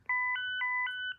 call_interrupted_by_admin.mp3